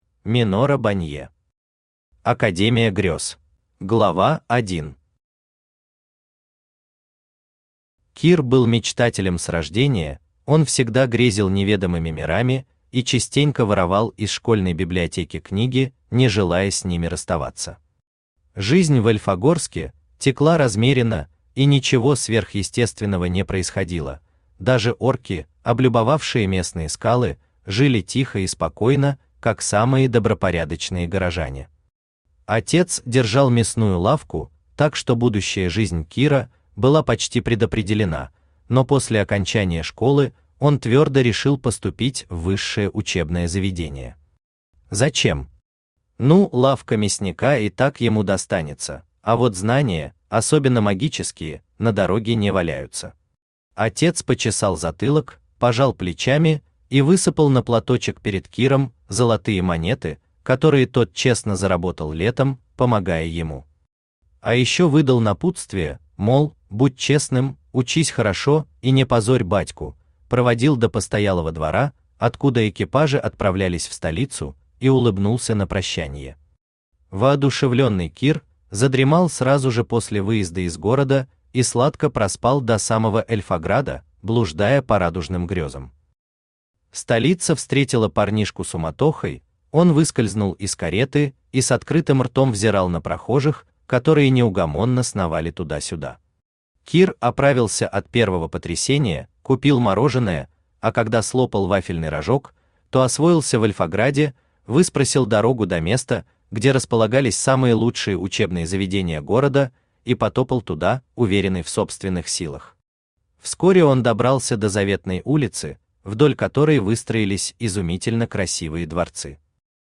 Аудиокнига Академия Грёз | Библиотека аудиокниг
Aудиокнига Академия Грёз Автор Минора Бонье Читает аудиокнигу Авточтец ЛитРес.